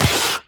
biter-roar-3.ogg